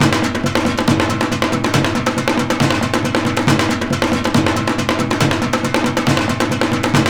KOREA PERC 1.wav